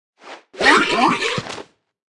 Media:Sfx_Anim_Super_Hog Rider.wavMedia:Sfx_Anim_Ultra_Hog Rider.wavMedia:Sfx_Anim_Ultimate_Hog Rider.wav 动作音效 anim 在广场点击初级、经典、高手、顶尖和终极形态或者查看其技能时触发动作的音效
Sfx_Anim_Classic_Hog_Rider.wav